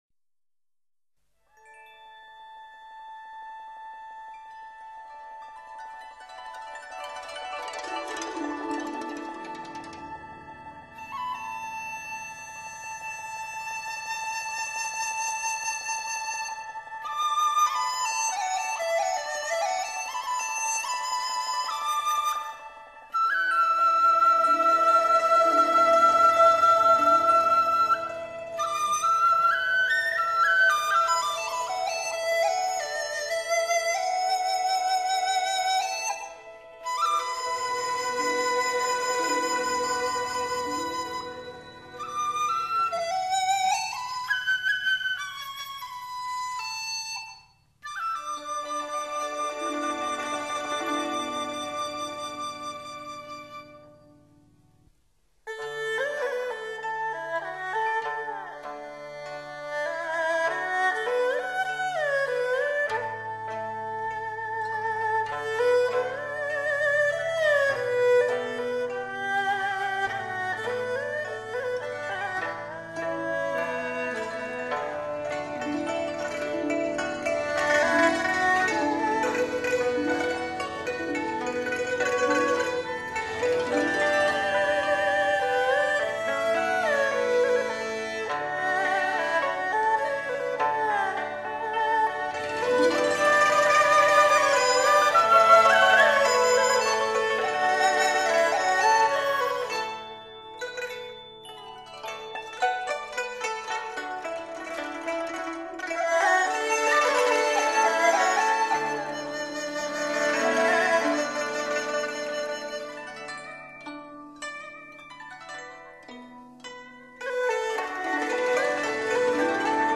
致柔致美的江南丝竹演绎
二胡笛箫悠扬绵长，朦胧中青石巷古朴清幽，彩虹桥如梦似幻。
扬琴月琴玲珑剔透，亭台楼阁流光溢彩。
重奏